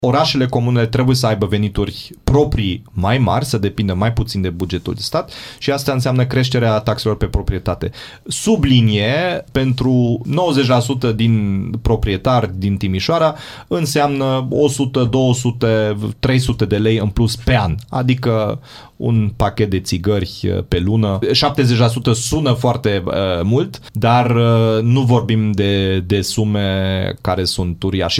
Primarul Timișoarei spune că impozitele pe proprietățile rezidențiale sunt mici în comparație cu investițiile necesare.